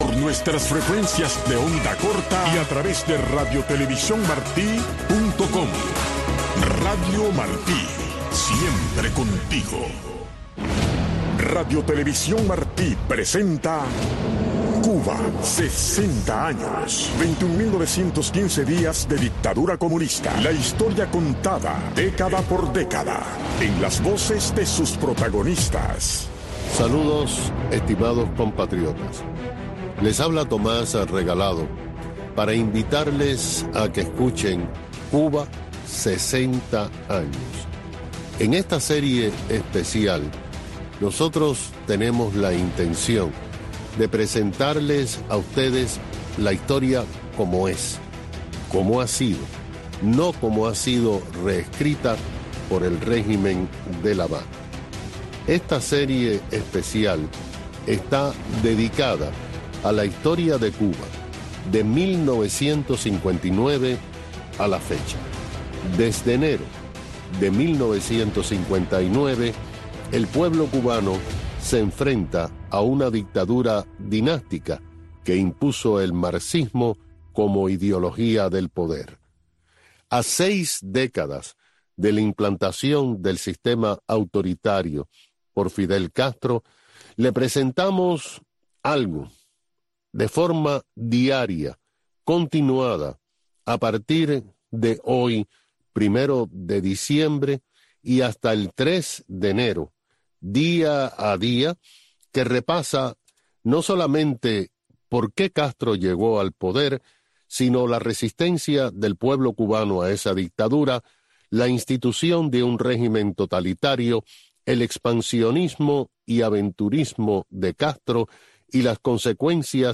Noticiero de Radio Martí 10:00 PM